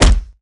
Punch Boxing Body Hit Sound
human